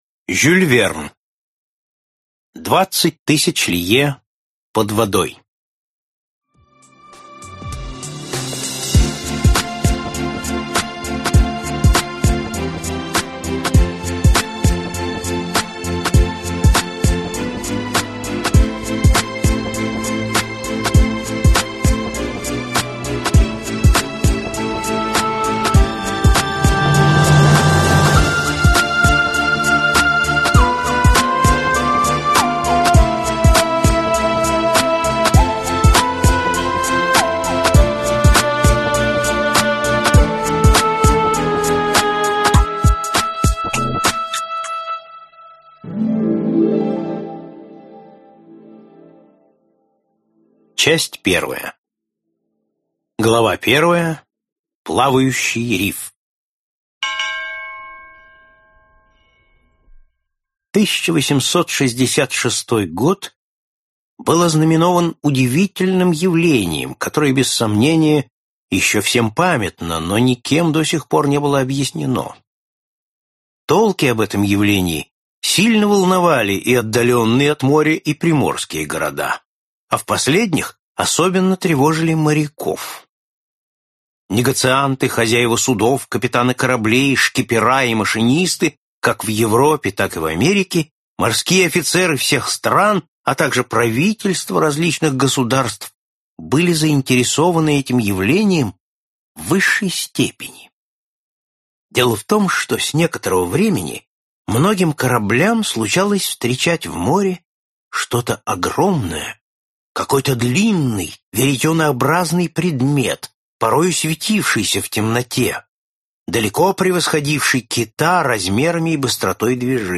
Аудиокнига Капитан Немо | Библиотека аудиокниг